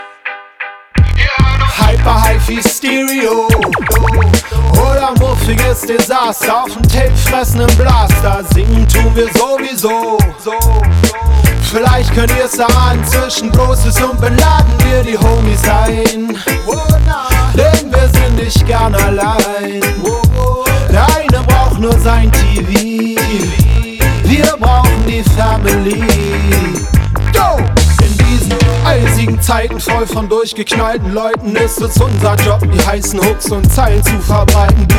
Жанр: Реггетон